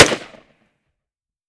fps_project_1/5.56 M4 Rifle - Gunshot A 001.wav at d65e362539b3b7cbf77d2486b850faf568161f77 - fps_project_1 - Gitea: Git with a cup of tea